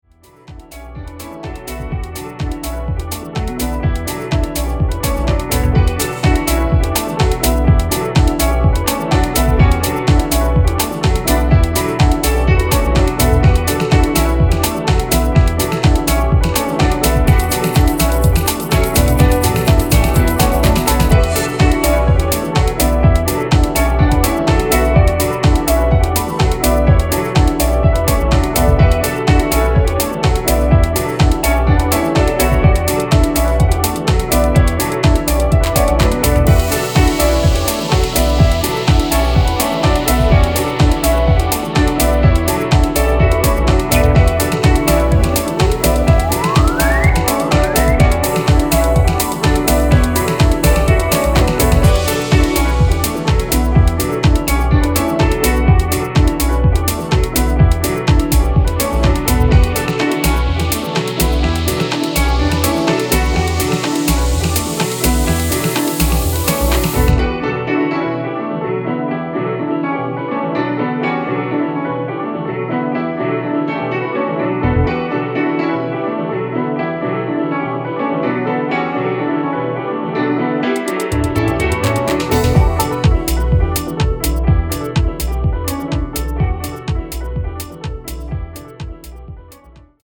有機的かつ叙情的なメロディーを湛えながら重心の安定したグルーヴを紡いでいく開放的なディープ・ハウス秀作